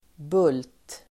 Uttal: [bul:t]